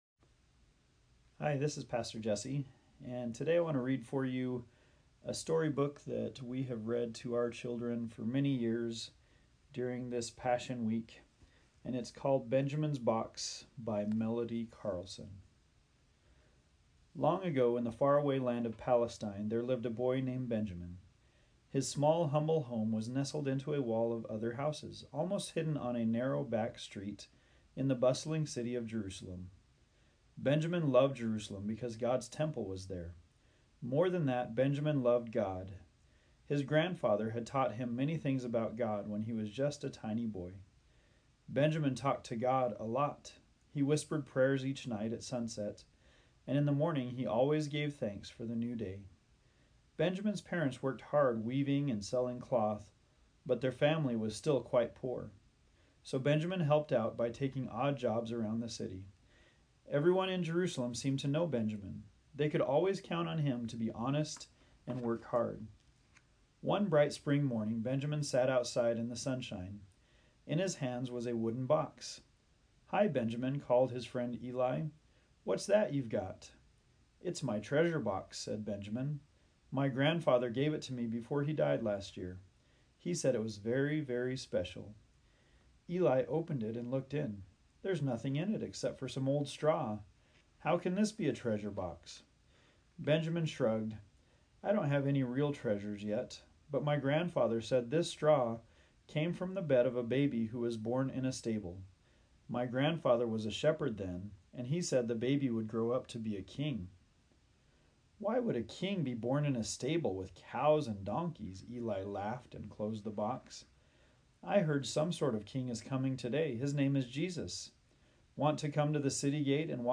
A Story